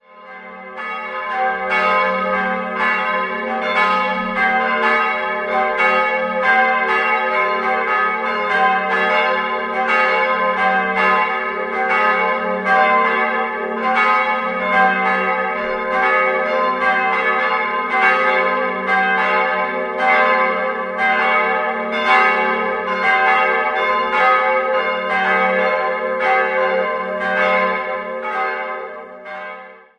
3-stimmiges Gloria-Geläute: g'-a'-c''